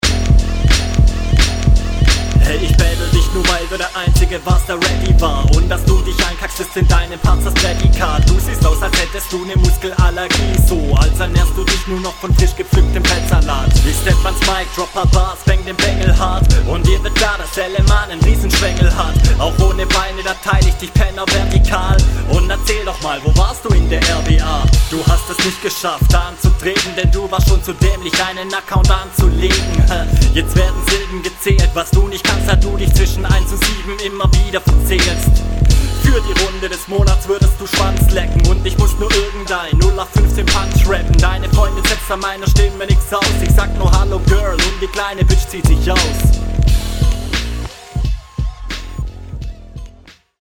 Ich find den Beat nicht so top für dich, trotzdem bleibst du sicher darauf.